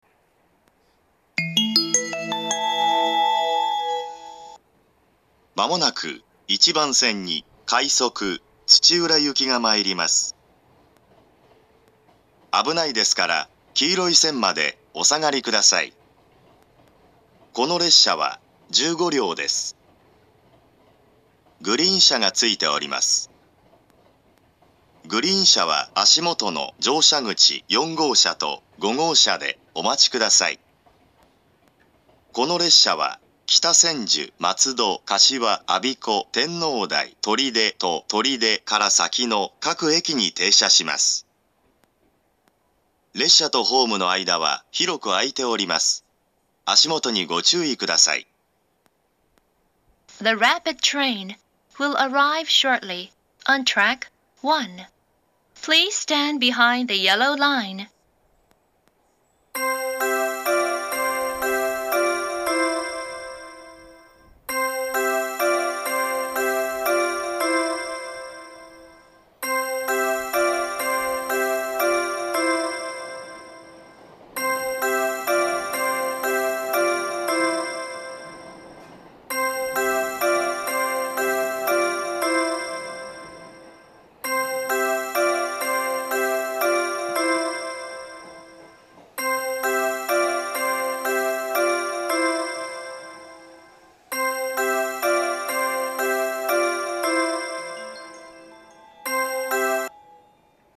２０１４年１２月１５日には、２０１５年３月開業の上野東京ラインに対応するため、自動放送の男声が変更されています。
１番線接近放送